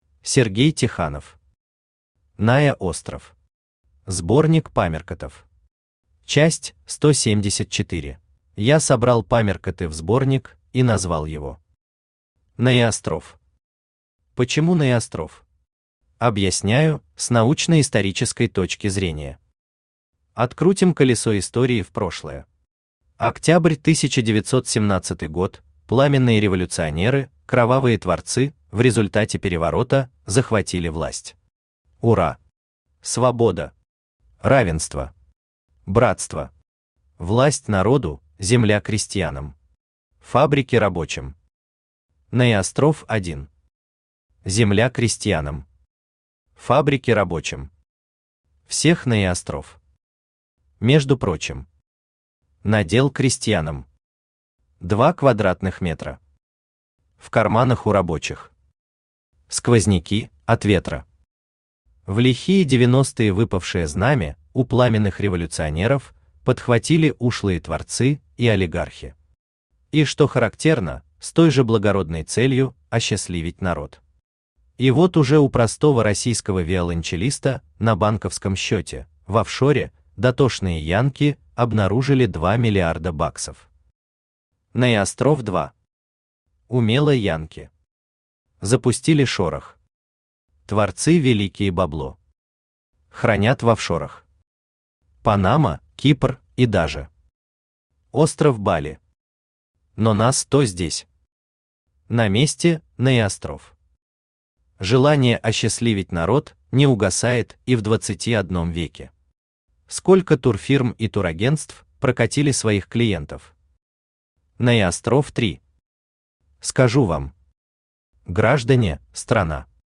Аудиокнига НаеОстров. Сборник памяркотов. Часть 174 | Библиотека аудиокниг
Читает аудиокнигу Авточтец ЛитРес.